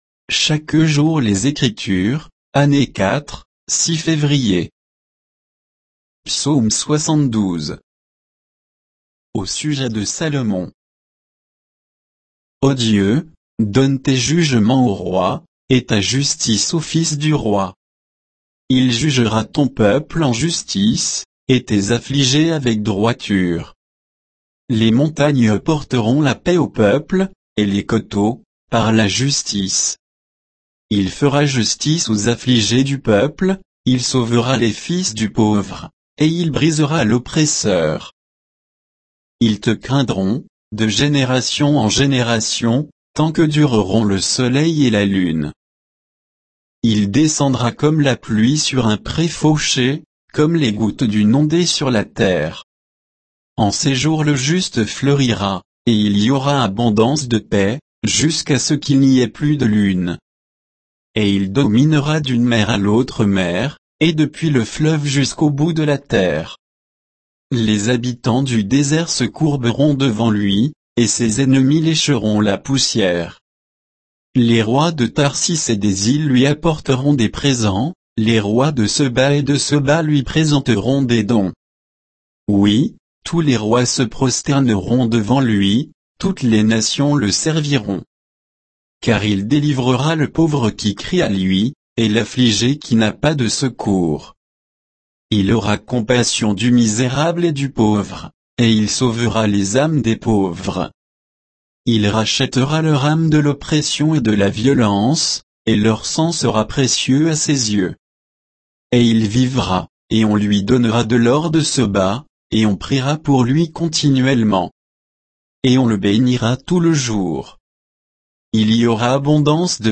Méditation quoditienne de Chaque jour les Écritures sur Psaume 72